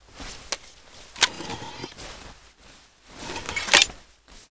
eryx_reload_1p.wav